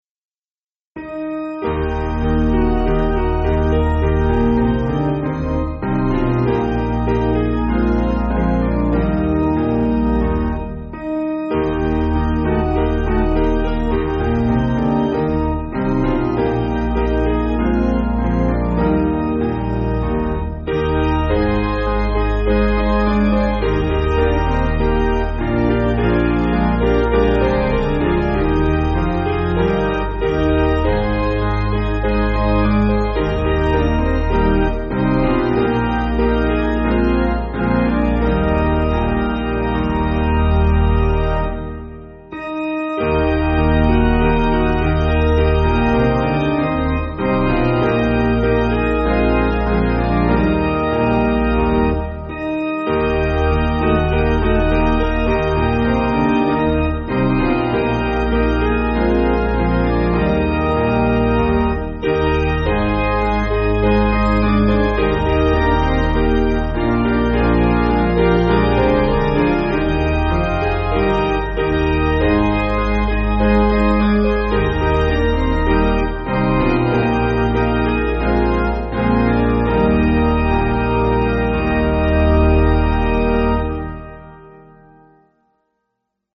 Basic Piano & Organ
(CM)   2/Eb